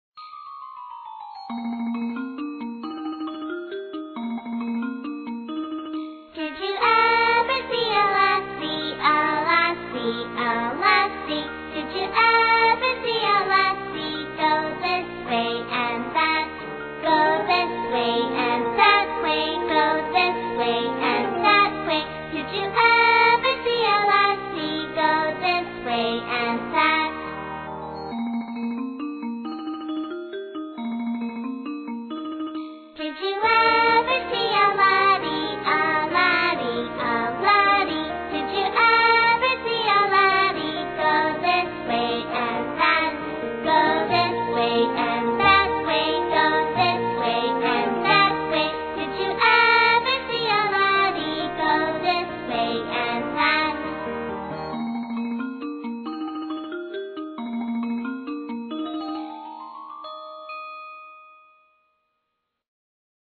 在线英语听力室英语儿歌274首 第32期:Did You Ever See a Lassie(2)的听力文件下载,收录了274首发音地道纯正，音乐节奏活泼动人的英文儿歌，从小培养对英语的爱好，为以后萌娃学习更多的英语知识，打下坚实的基础。